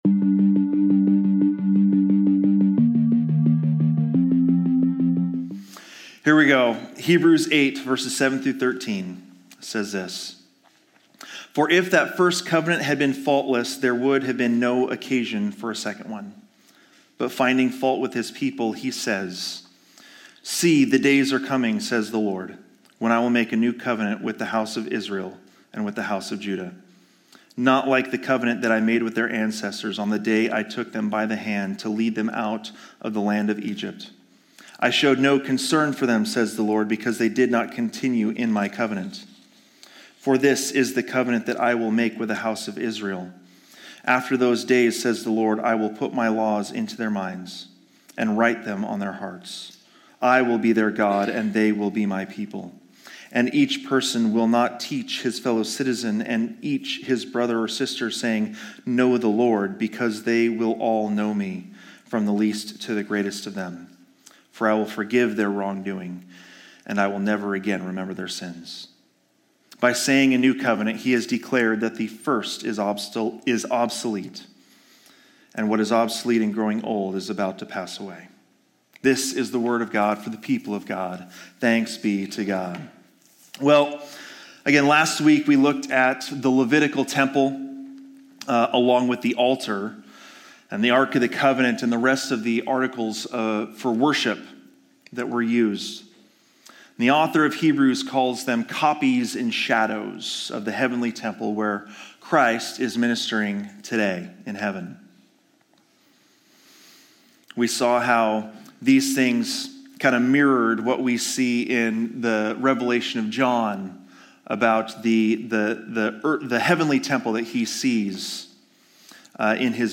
Sermons | Living Word Community Church